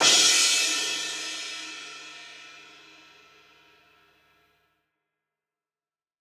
Key-rythm_cymbal_02-01.wav